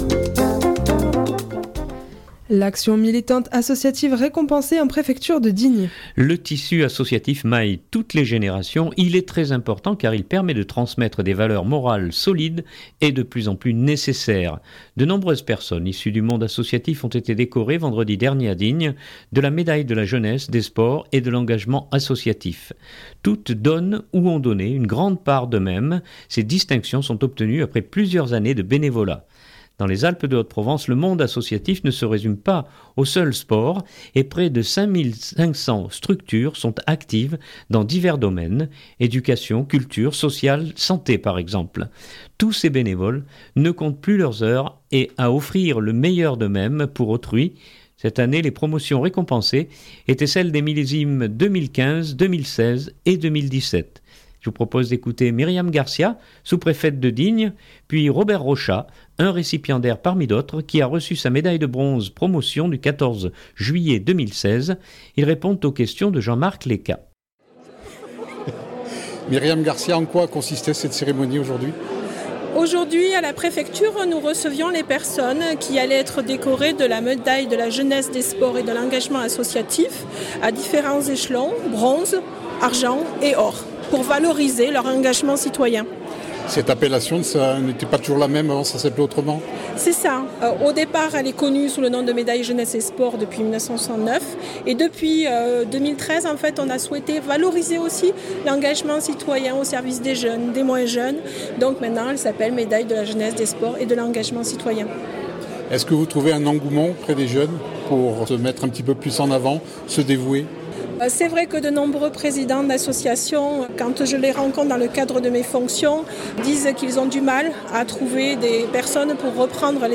Je vous propose d’écouter Myriam Garcia, sous-préfète de Digne